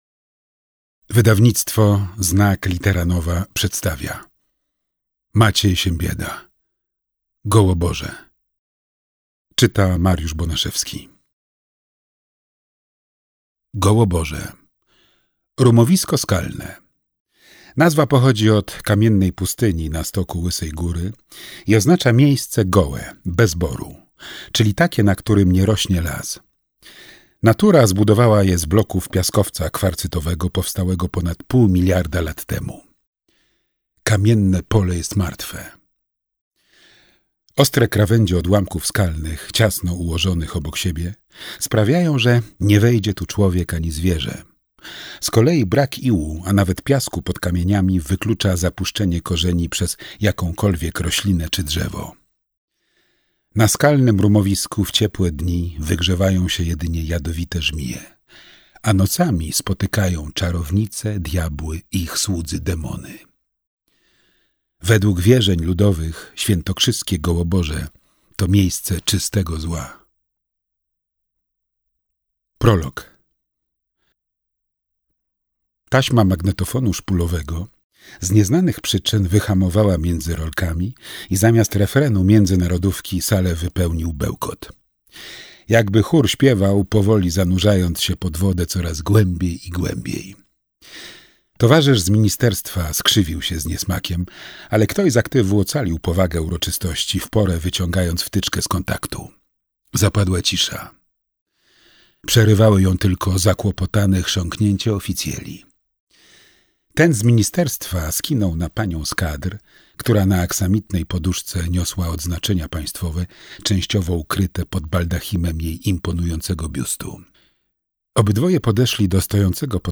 Gołoborze - Maciej Siembieda - audiobook